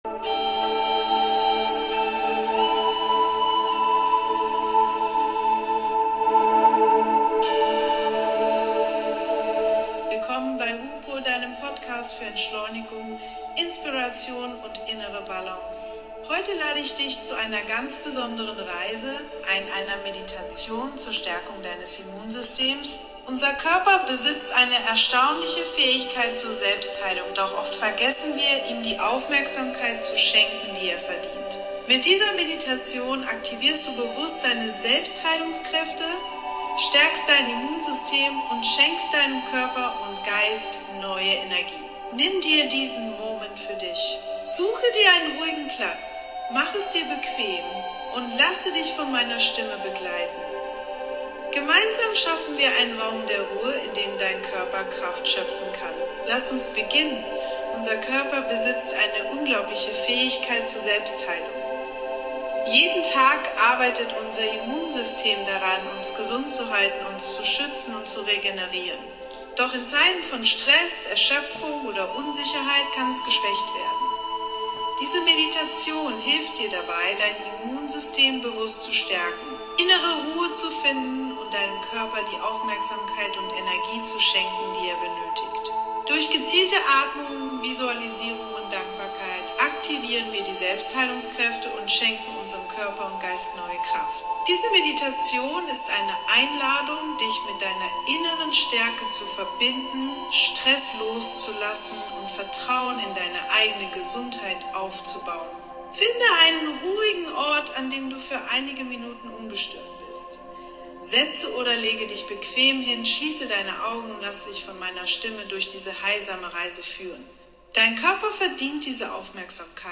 Ruhepol – Meditation zur Stärkung des Immunsystems Dein Körper ist